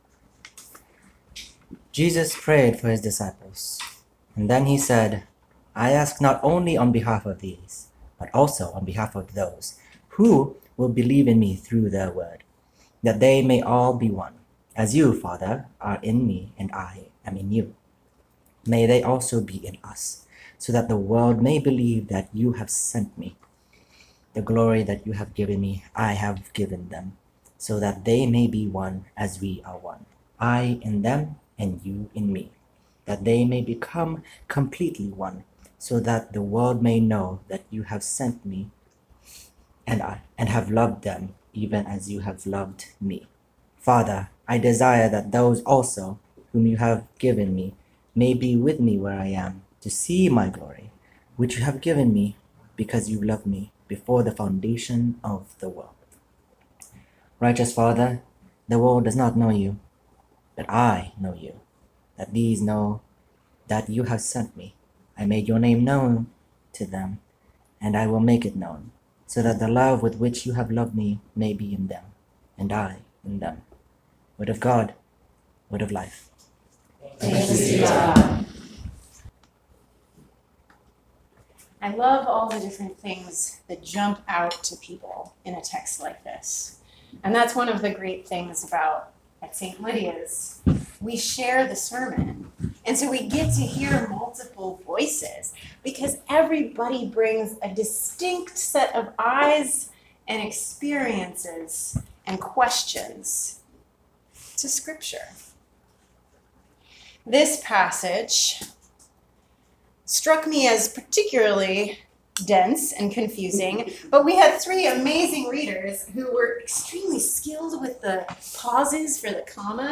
June 2, 2019 Sermon